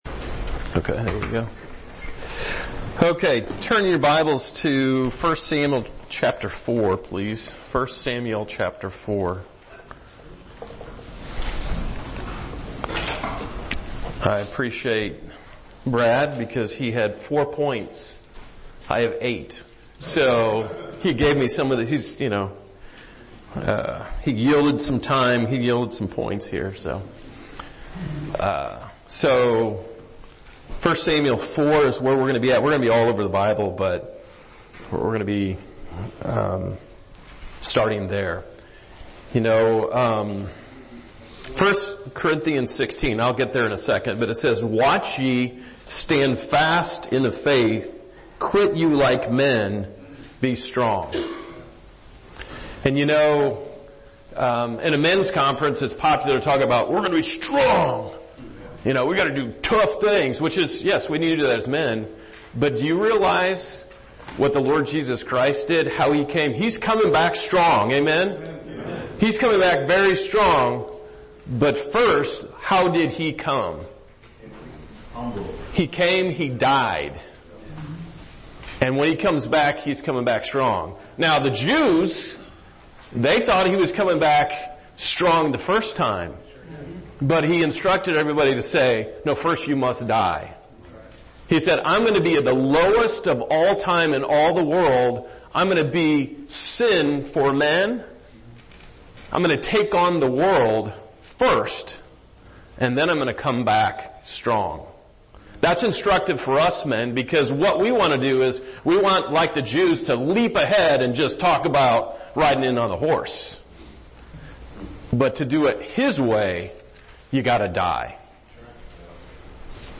2024 Men's Conference Current Sermon